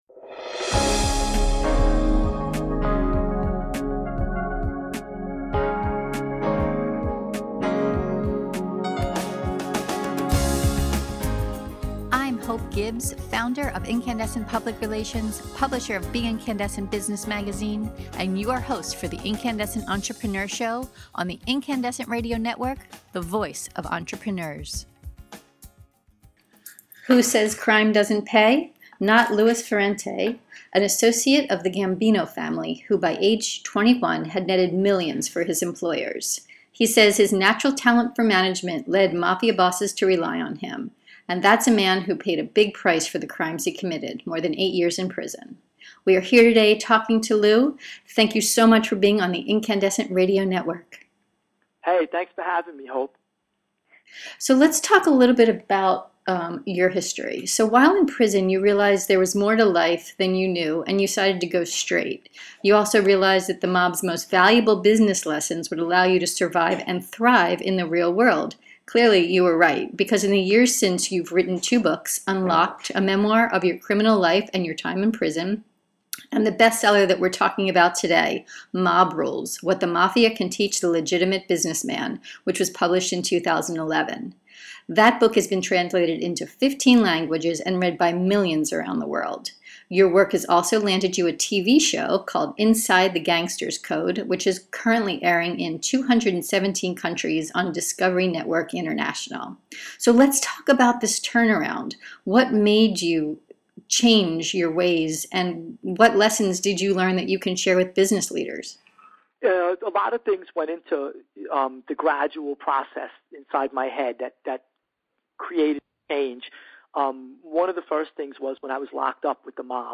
So it was a great pleasure to interview Lou about what made him change his ways — and want to share the lessons he’s learned with entrepreneurs? In this podcast interview we learn: What led Lou into a life of crime, and into the arms of the Mafia How he was finally caught and put into prison How he regained his freedom — and what made him eventually decide to go straight How we can all learn from his experience to defend against predators, sniff out bullshitters, and outfox a snake.